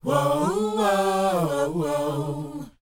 WHOA D C.wav